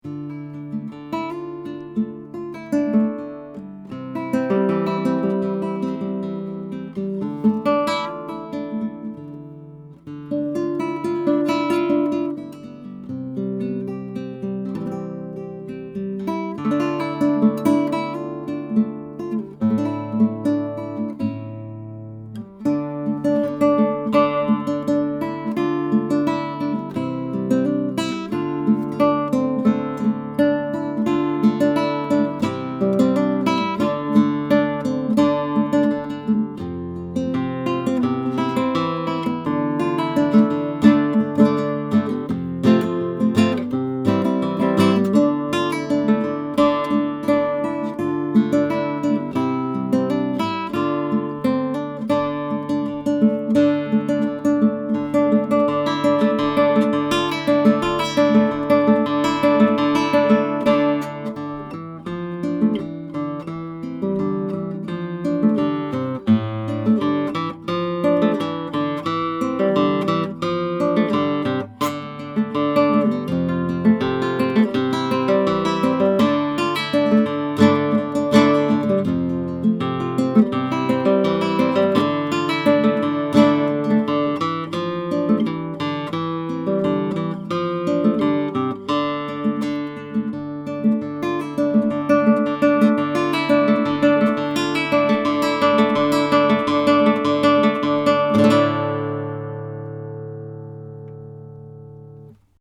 The guitar has a wonderfully sweet sound, with singing trebles and beautiful, deep basses, and excellent resonance and sympathetic sustain.
These MP3 files have no compression, EQ or reverb -- just mic'd through Schoeps and Neumann microphoness, using various patterns, into a Presonus ADL 600 preamp into a Rosetta 200 A/D converter.
SCHOEPS CMC 441 HYPERCARDIOID: